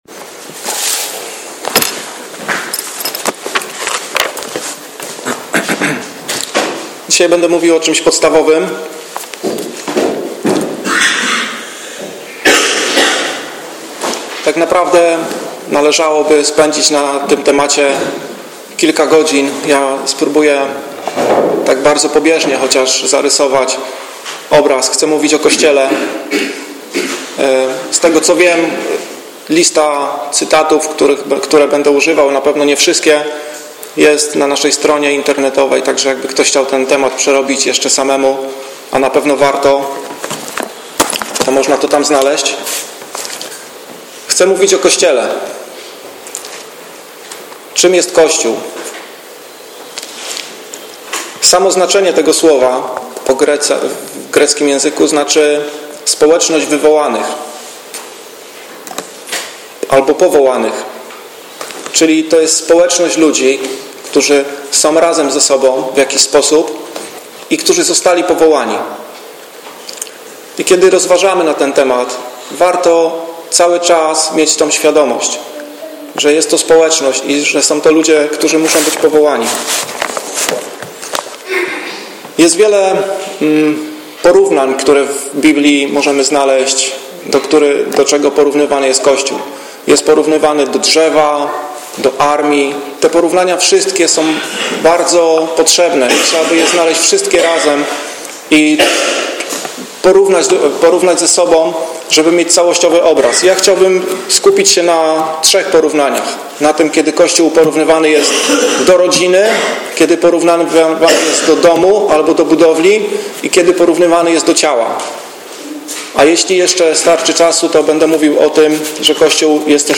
Na dzisiejszym kazaniu, mogliśmy usłyszeć czym jest kościół.
Niestety z przyczyn technicnzych, nagrała się pewena część kazania.